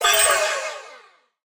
Minecraft Version Minecraft Version 1.21.5 Latest Release | Latest Snapshot 1.21.5 / assets / minecraft / sounds / mob / allay / item_taken2.ogg Compare With Compare With Latest Release | Latest Snapshot
item_taken2.ogg